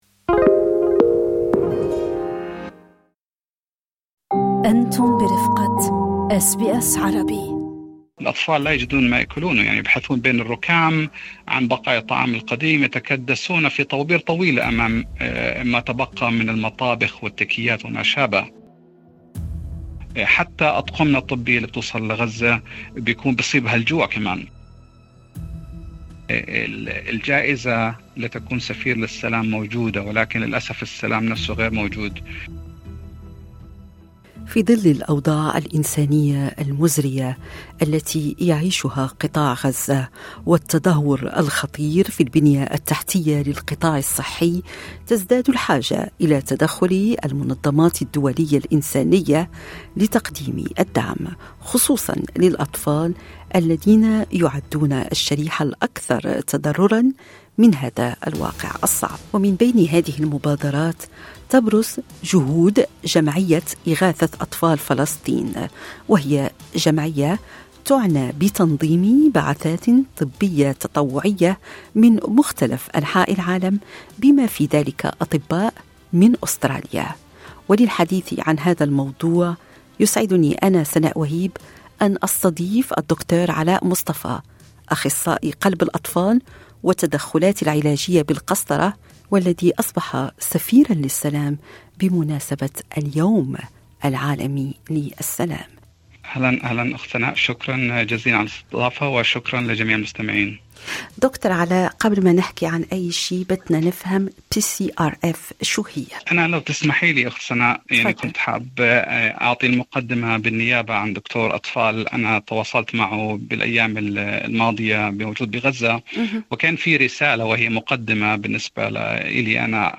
في لقاء معه، تحدث عن الدور الكبير الذي تلعبه الجمعية في ظل الأوضاع الاستثنائية التي يعاني منها القطاع الصحي في غزة.